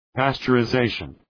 Προφορά
{,pæstʃərə’zeıʃən}